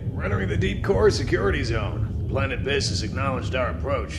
— Shug Ninx Source Dark Empire audio drama Licensing This is an Ogg Vorbis sound sample.